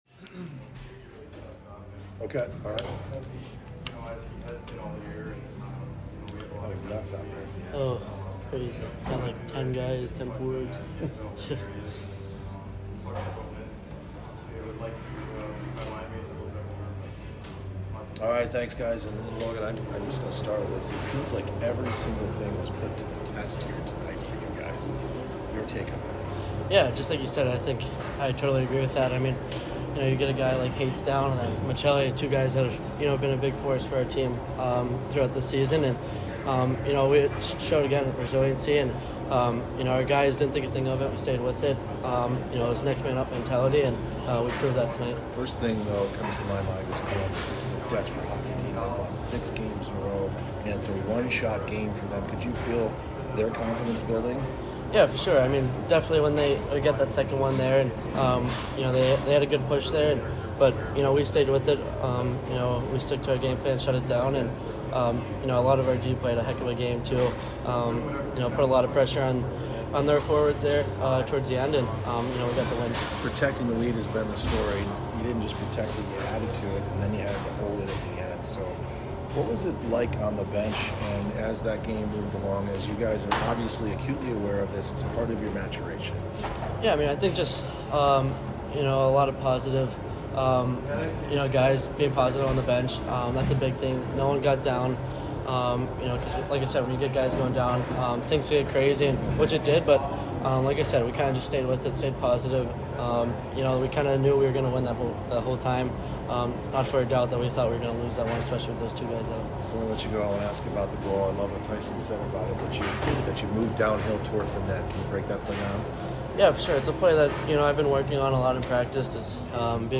COYOTES POST-GAME AUDIO INTERVIEWS
F Logan Cooley